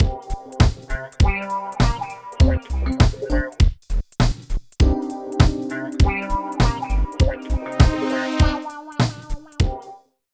110 LOOP  -L.wav